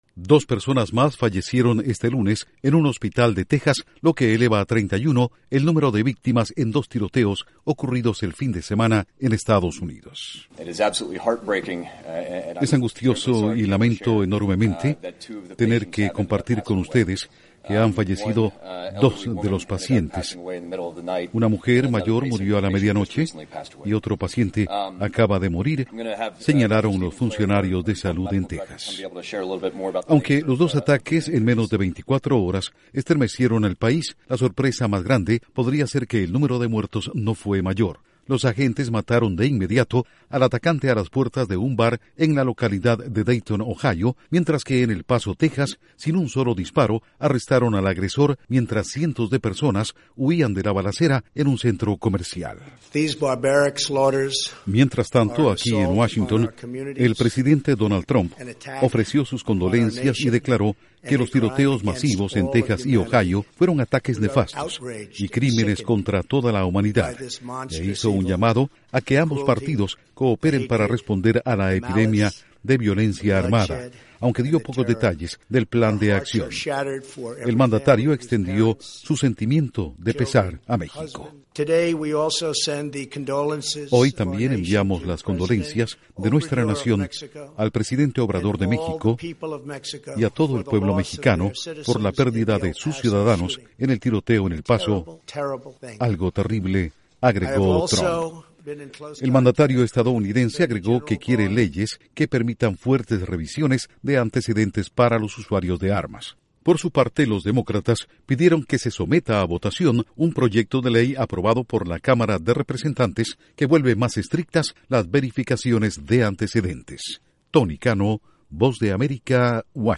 Duración: 2:00 Audios: 1-Informe de Hospitales 2-Reacción de Trump/Republicano 3-Reacción de demócratas